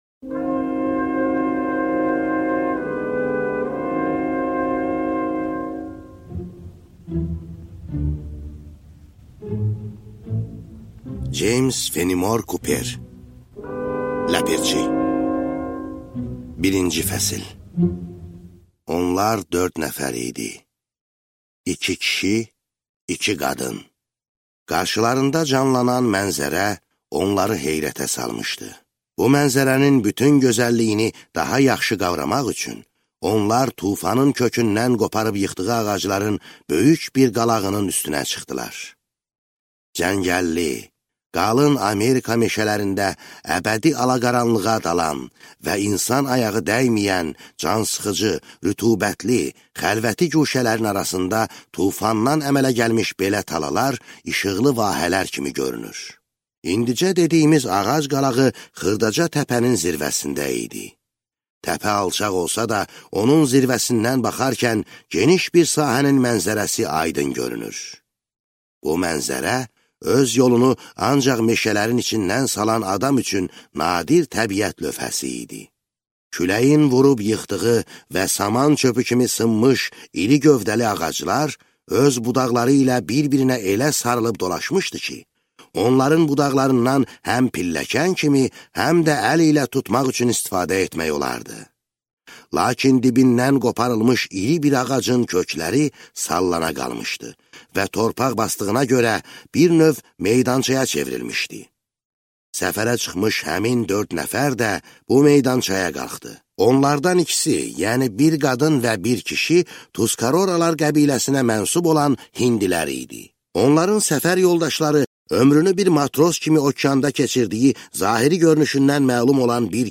Аудиокнига Ləpirçi